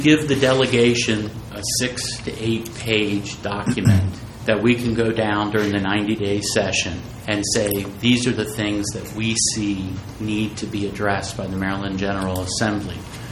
Now, it has come together again to address the May 2025 flood recovery efforts.  Senator Mike McKay relayed an update to the Allegany County Commissioners on efforts of the committee including action items and expectations around specific needs that may be accomplished through legislation…